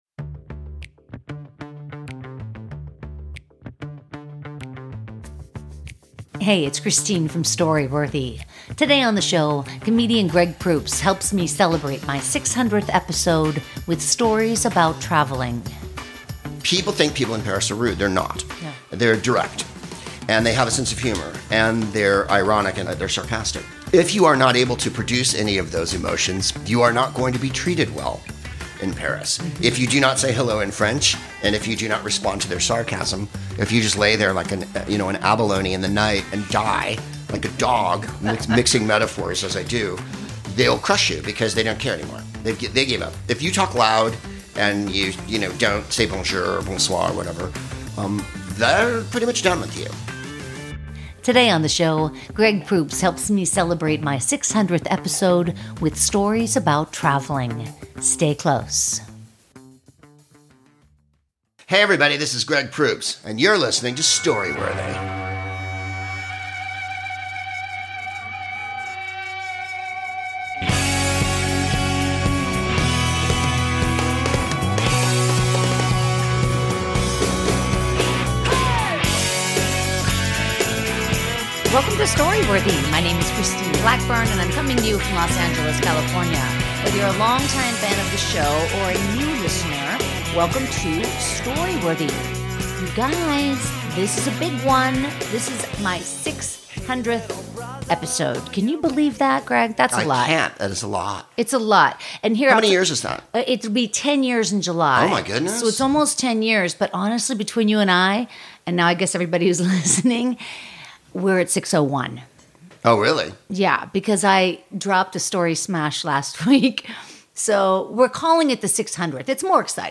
Comedian Greg Proops (The Smartest Man in the World, Whose Line Is It Anyway? Schooled) helps celebrate the 600th episode of Story Worthy with stories about traveling to France, England and Turkey.